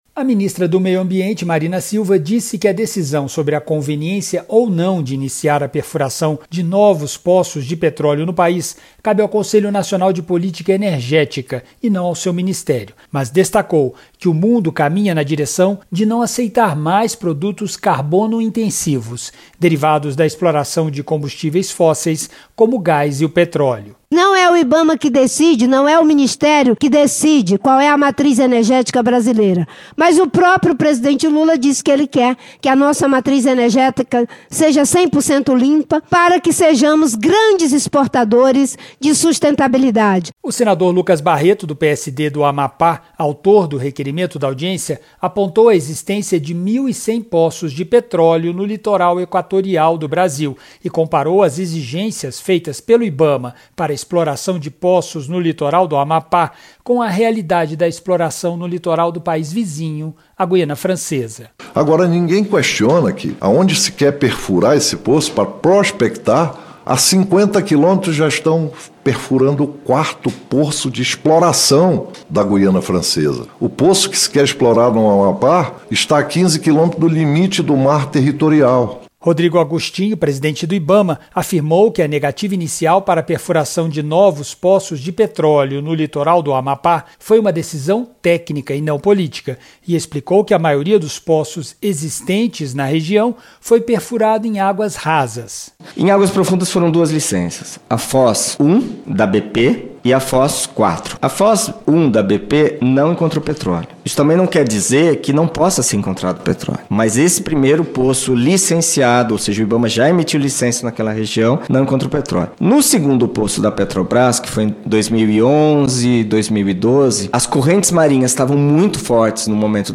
A Comissão de Infraestrutura (CI) discutiu o licenciamento ambiental para a exploração de petróleo no litoral do Amapá com a ministra do Meio Ambiente, Marina Silva.